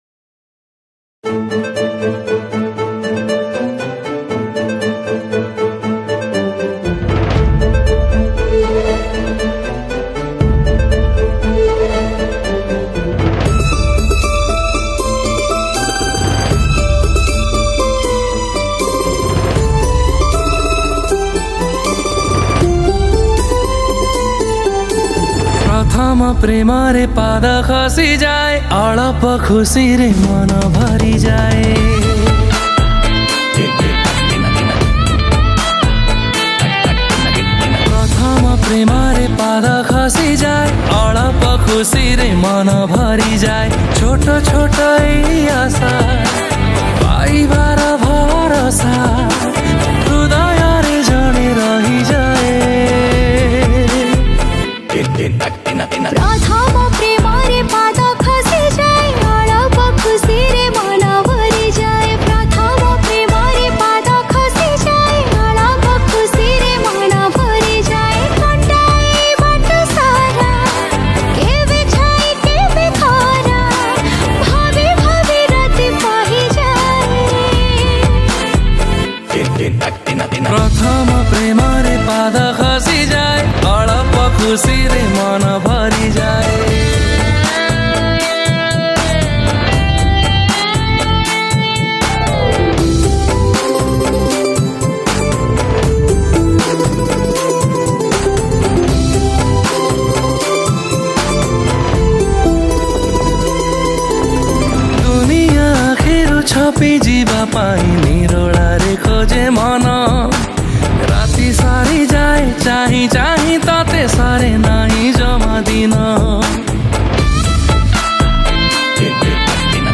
A Soft Romantic Song